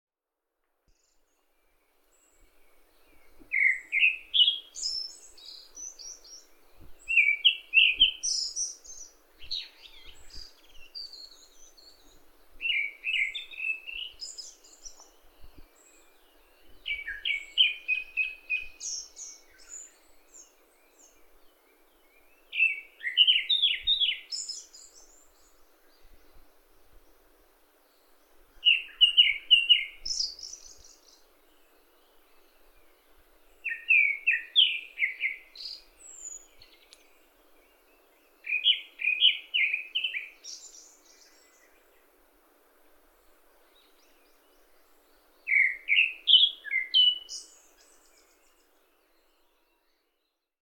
この暑さで周囲に人影もなく、雑音なしで鳴き声を録音できたのはラッキーだった。
◎　クロツグミ【黒鶫】　Japanese Thrush　スズメ目ヒタキ科ツグミ属　全長:22ｃｍ
【録音③】　 2024年7月18日　高尾山
10分近くさえずっていたが、50秒ほどのさえずりをお聞きください